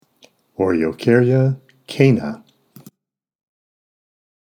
Pronunciation/Pronunciación:
O-re-o-cár-ya  cà-na